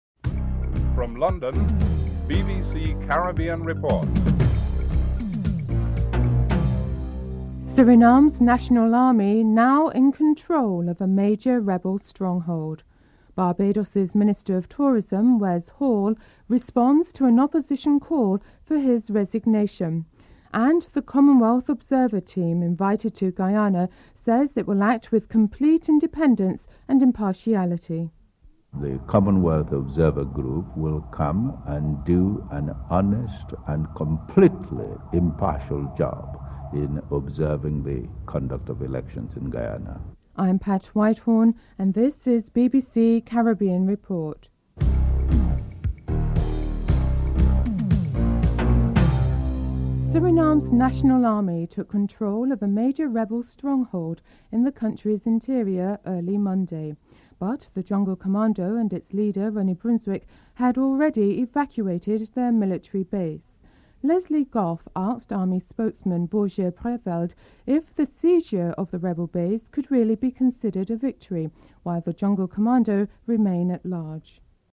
1. Headlines (00:00-00:49)
4. Financial News (08:17-09:05)